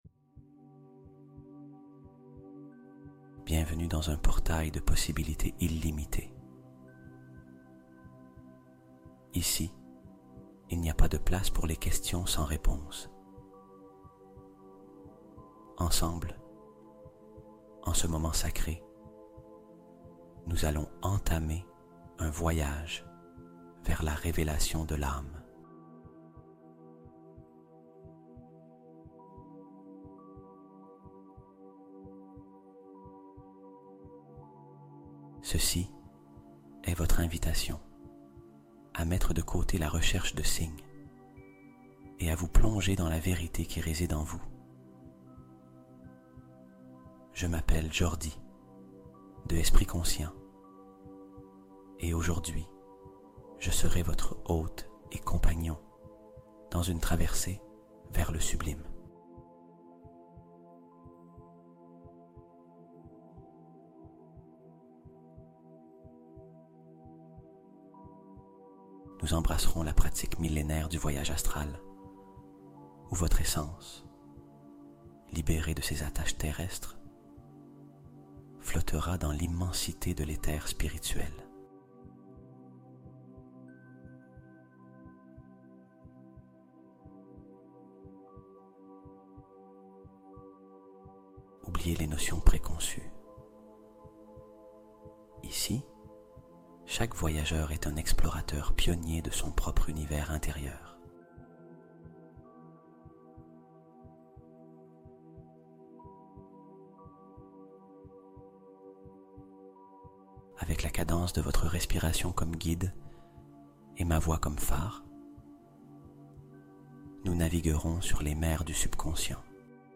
Boussole Intérieure : Voyage guidé pour obtenir une réponse de son inconscient